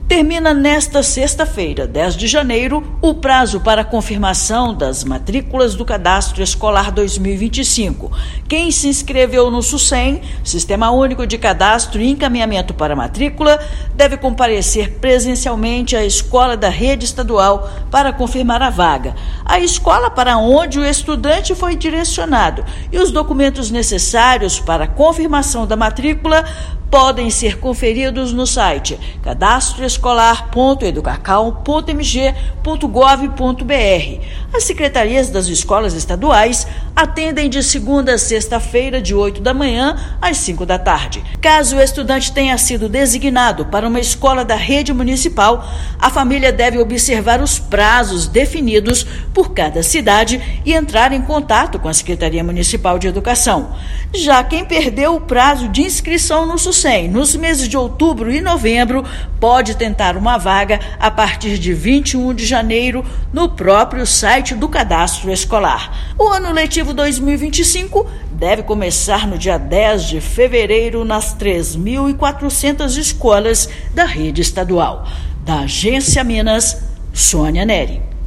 Responsáveis e estudantes maiores de idade devem comparecer presencialmente à escola designada para apresentar documentos e efetivar a matrícula. Ouça matéria de rádio.